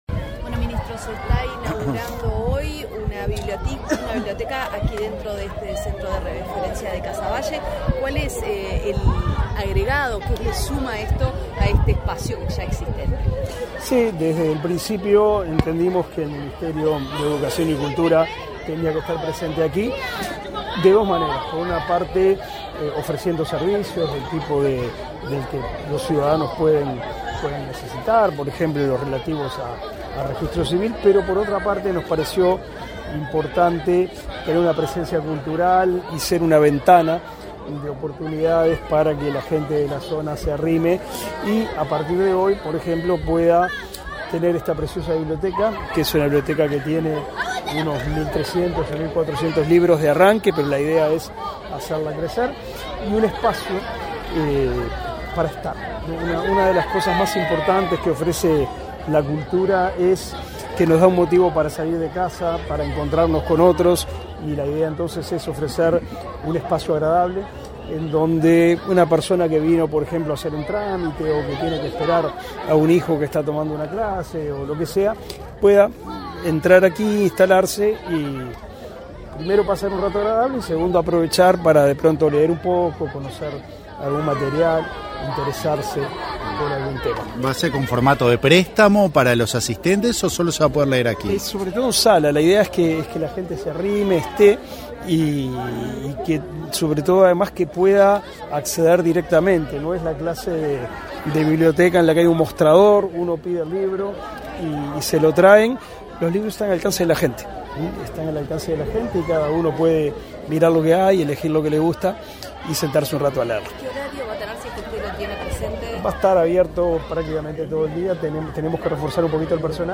Declaraciones a la prensa del ministro de Educación, Pablo da Silveira
Antes del evento, el ministro Pablo da Silveira realizó declaraciones a la prensa.